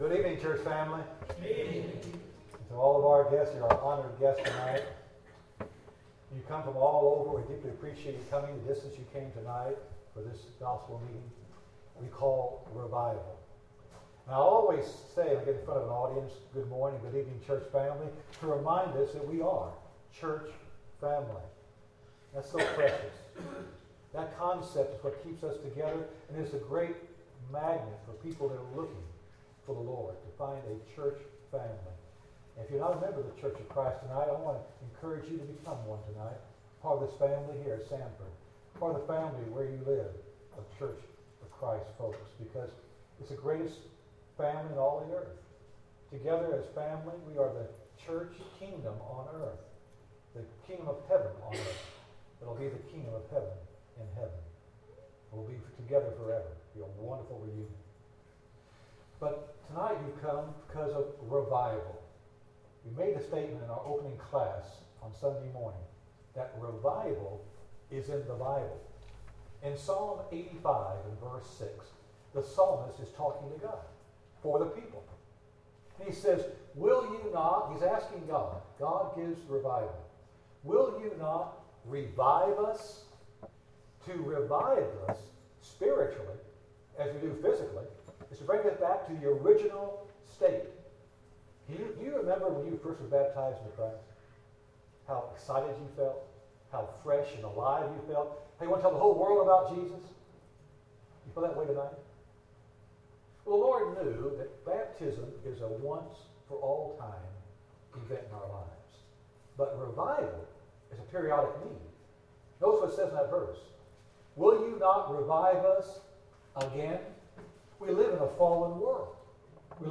Psalm 85:6 Service Type: Gospel Meeting Download Files Notes Topics: Revival « How Does Revival Come?